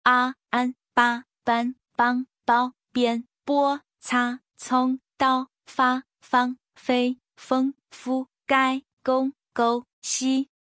第一声の漢字と発音